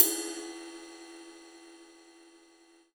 • Big Drum Crash E Key 06.wav
Royality free drum crash sound tuned to the E note. Loudest frequency: 10401Hz
big-drum-crash-e-key-06-vSL.wav